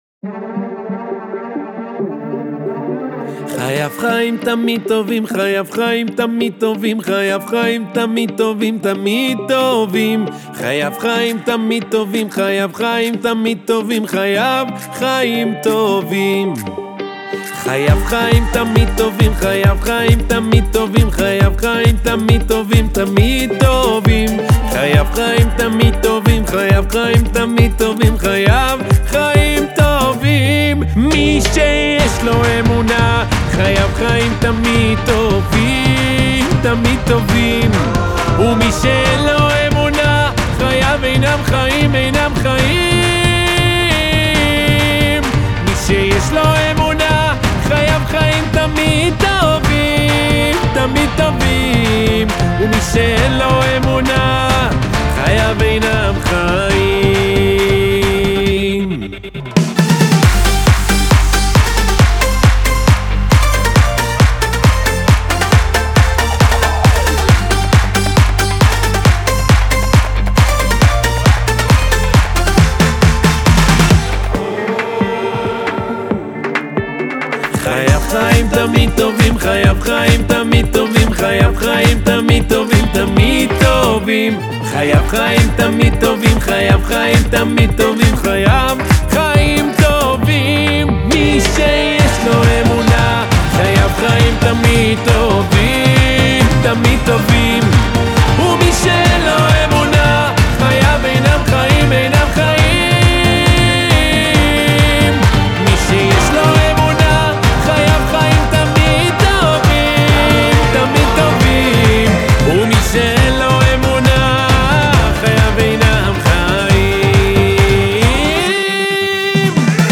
מוזיקה יהודית